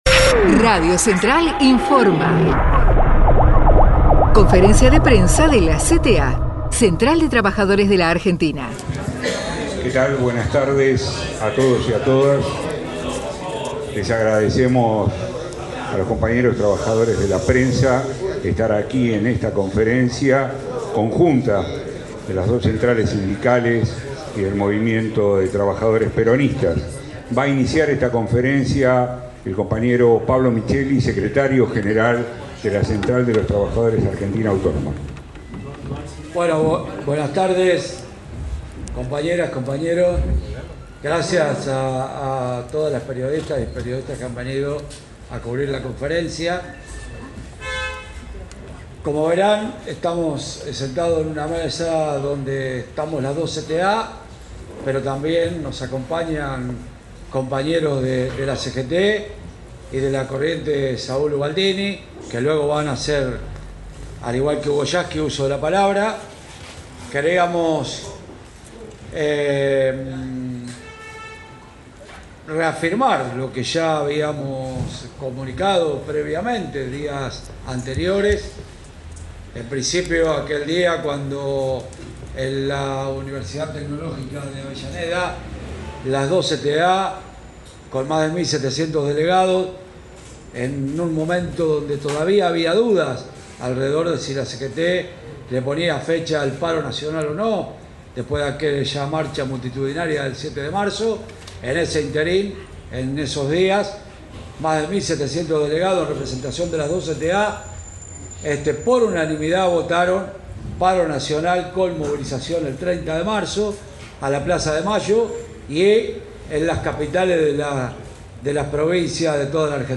30M // Conferencia de prensa: PABLO MICHELI